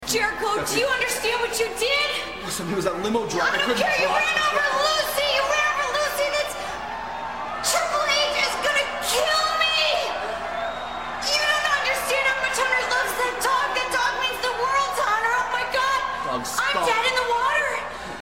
Steph goes into total freakout mode,
overacting to a level that made her horrible overacting earlier seem Oscar worthy in comparison.